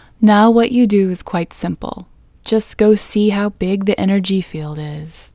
OUT Technique Female English 5 | Inner Greatness Global